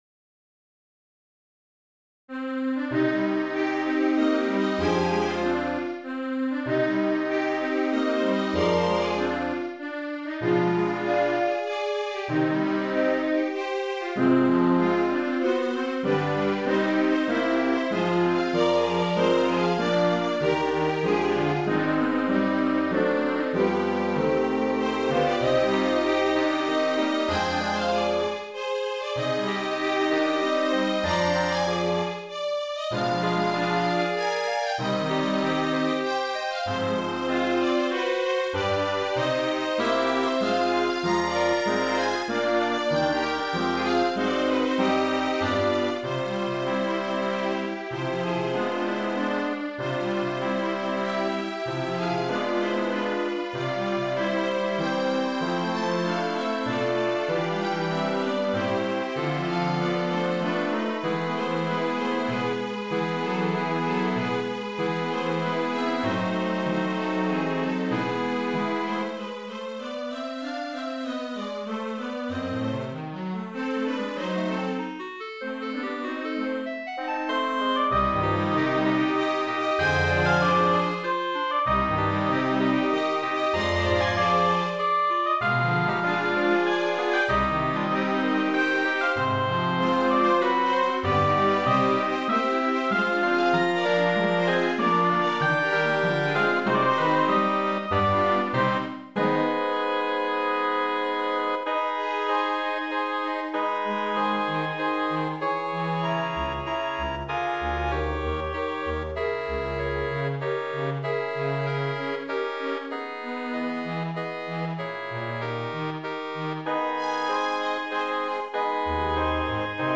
最近の流行りとは違いますがテンポ早い目で
リズムをしっかり出してみました。